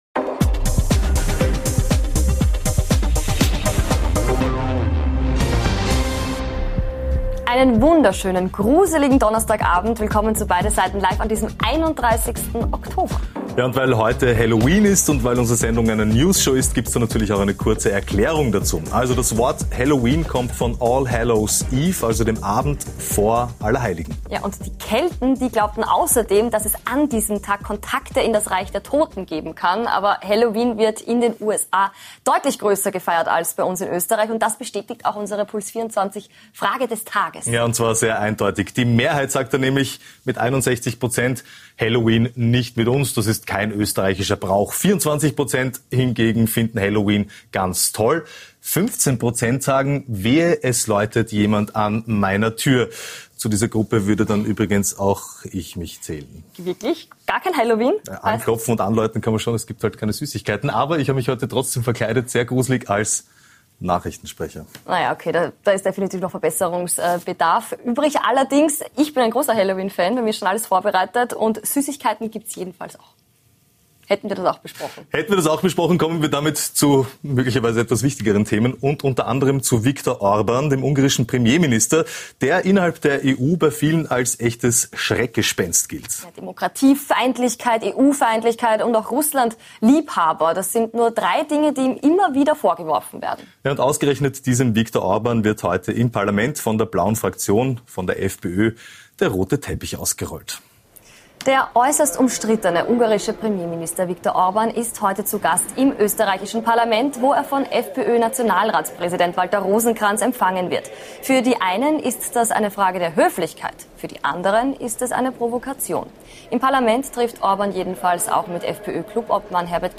Schwarz-Rote Koalitionssuche: Wo treffen sich ÖVP und SPÖ beim Thema Wirtschaft Und nachgefragt haben wir heute bei gleich drei Gästen: Im Interview des Tages ist der Grüne Vizekanzler Werner Kogler zu Gast.